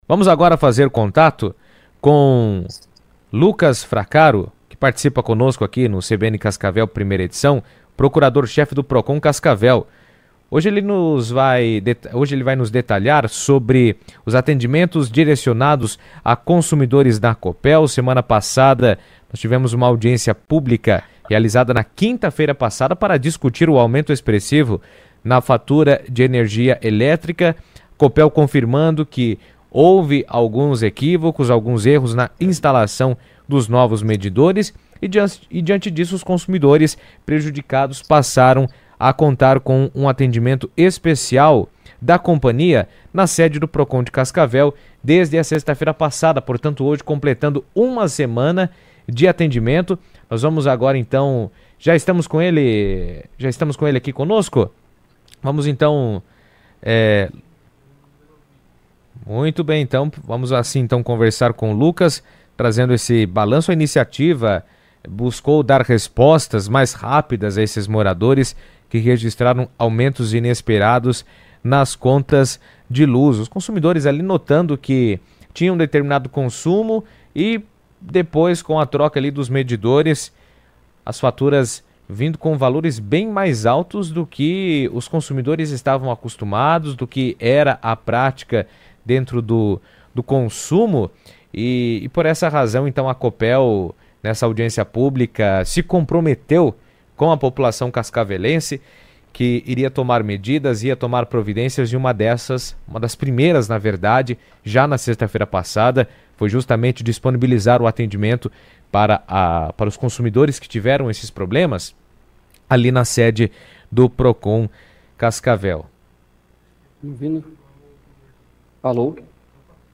Lucas Fracaro, Procurador-Chefe do Procon Cascavel, falou à CBN Cascavel sobre a primeira semana de atendimento aos clientes da Copel que estão com problemas relacionados a fatura de energia e a troca dos medidores inteligentes.